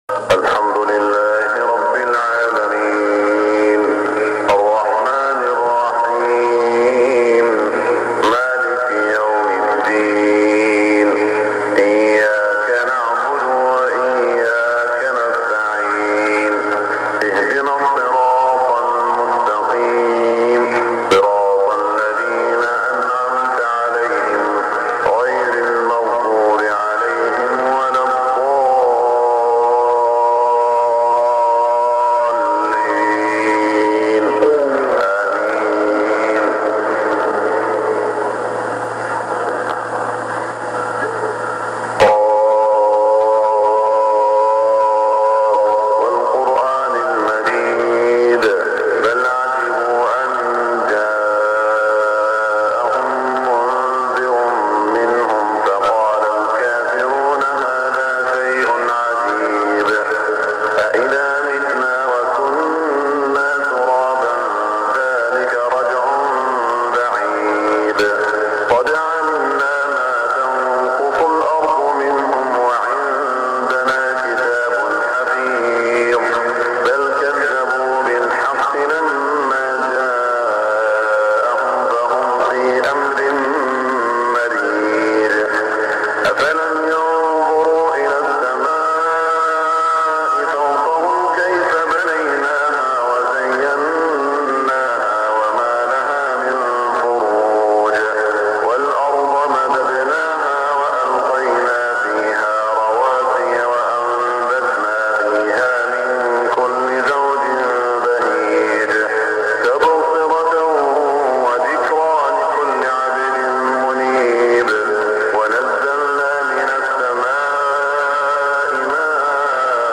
صلاة الفجر 1418هـ من سورة ق > 1418 🕋 > الفروض - تلاوات الحرمين